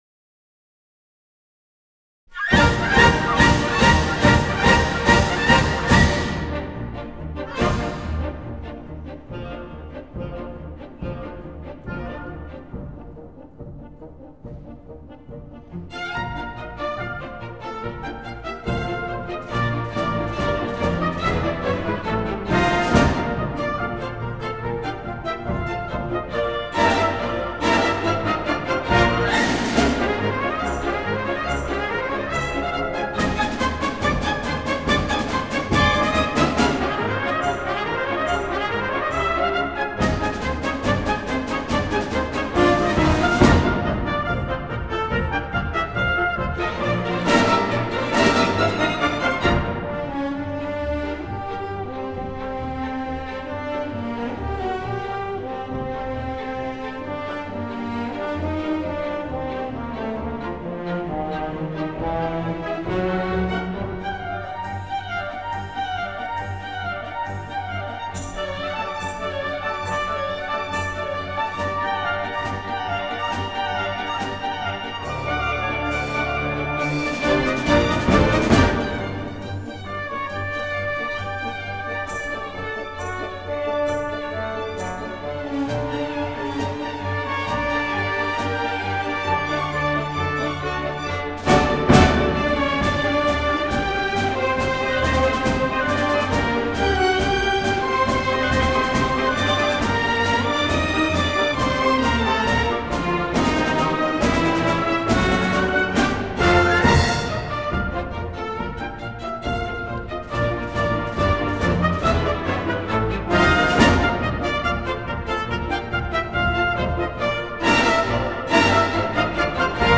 作曲家運用樂器來刻劃人物和動物的性格、動作和神情，音樂技巧成熟，形式新穎活潑，旋律通俗易懂。